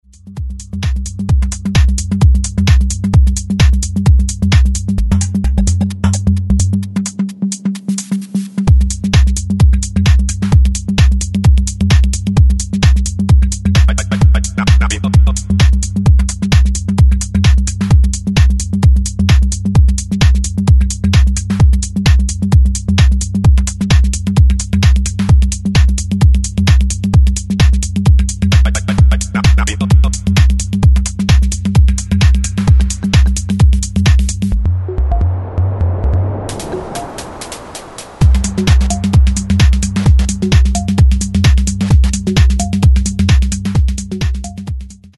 Styl: Techno, Minimal